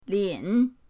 lin3.mp3